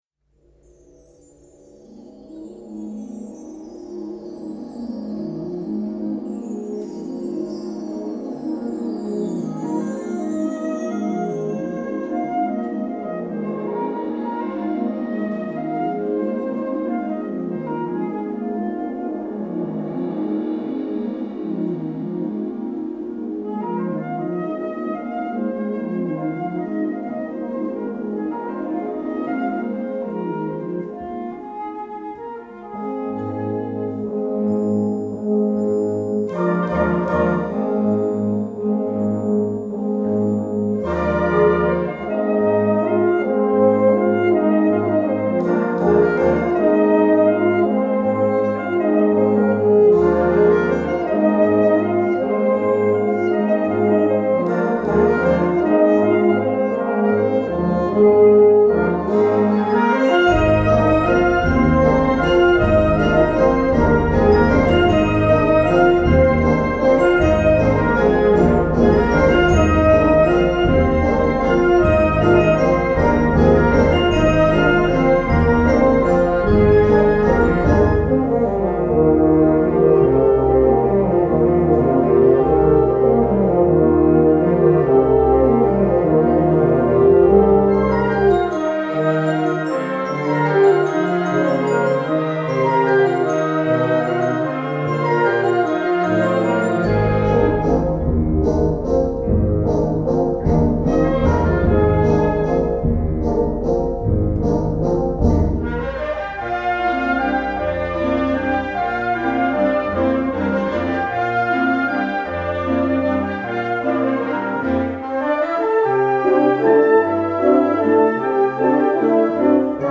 6:50 Minuten Besetzung: Blasorchester PDF